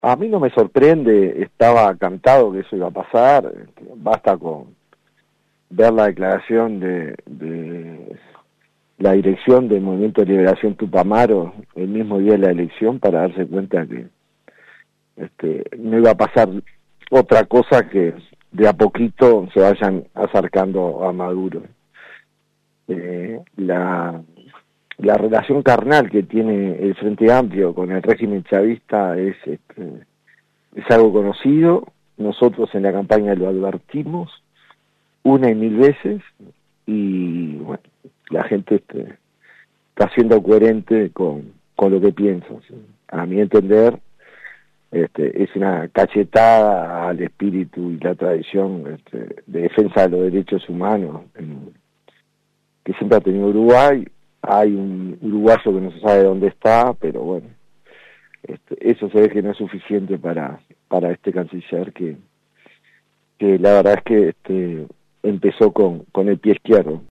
Escuche a Sebastián Da Silva aquí:
El senador de la lista 40 del Partido Nacional, Sebastián Da Silva, criticó, en una entrevista con 970 Noticias, los dichos del canciller de la República, Mario Lubetkin quien aseguró que Uruguay ya no reconoce a Edmundo González Urrutia como presidente de Venezuela, pero tampoco lo hace con Nicolás Maduro.